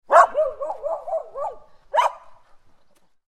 Animal Sounds / Dog Sounds / Sound Effects
Medium-sized-dog-barks.mp3